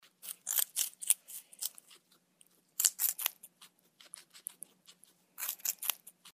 Звуки морских свинок
Грызет пищу